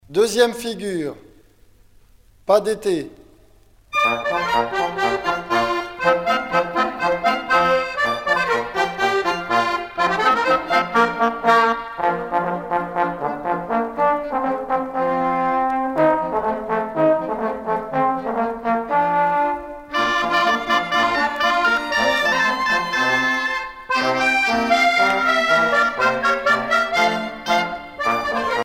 danse : quadrille : pas d'été
Pièce musicale éditée